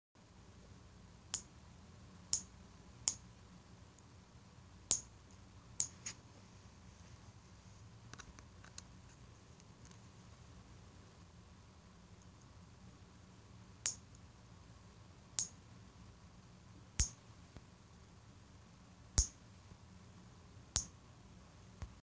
Nail Clipper